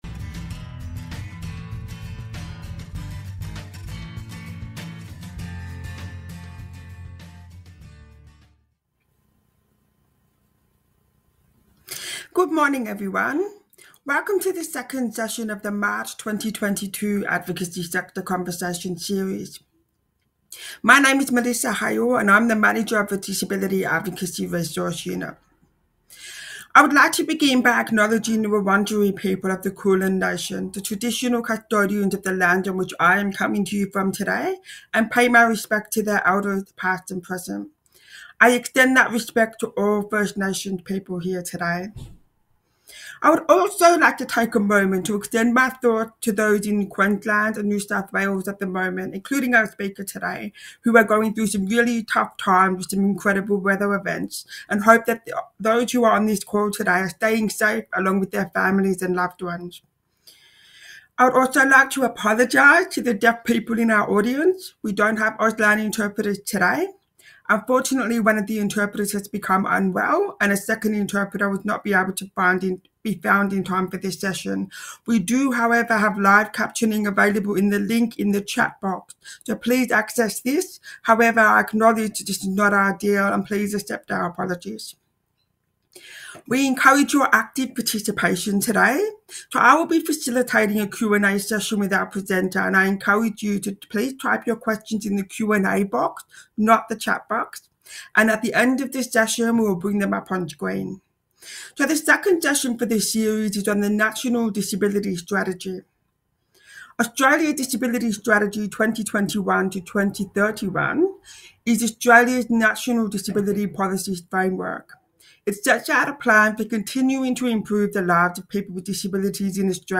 This session was part of the Advocacy Sector Conversations Forum series held on Zoom 2 March 2022.